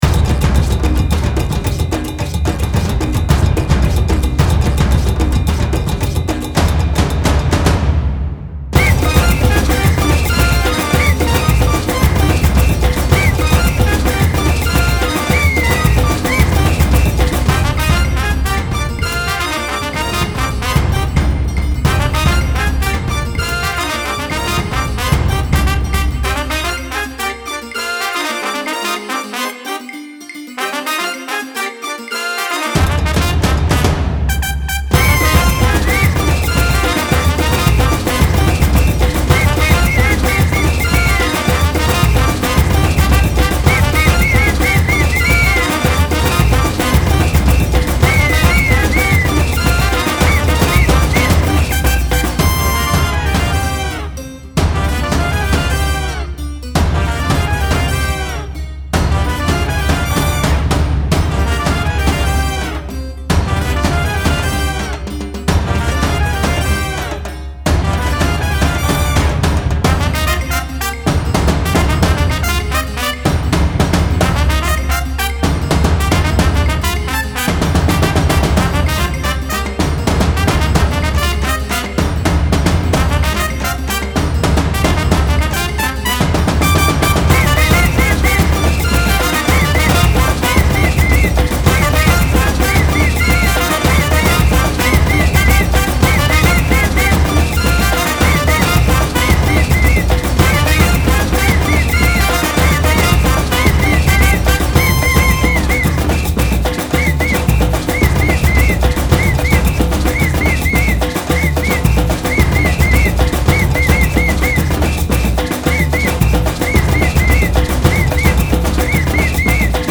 Style Style World
Mood Mood Intense, Uplifting
Featured Featured Acoustic Guitar, Brass, Drums +2 more
BPM BPM 110